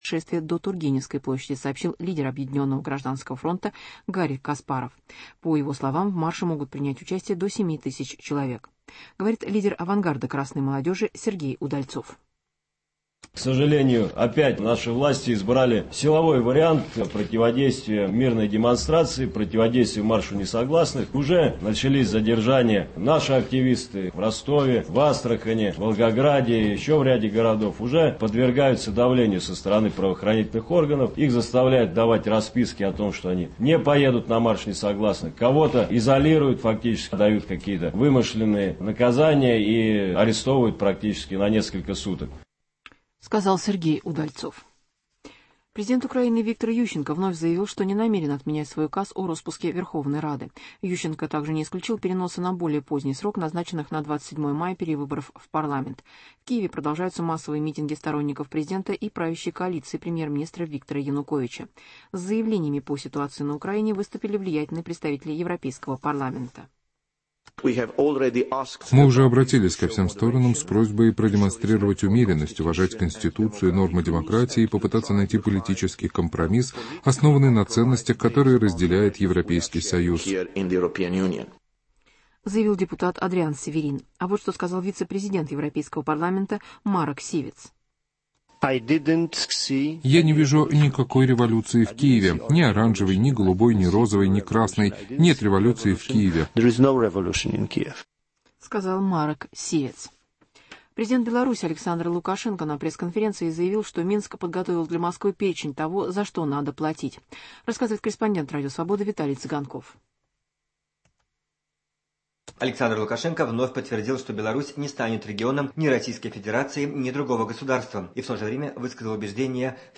В программе выступит член политсовета партии "Союз правых сил" Борис Немцов.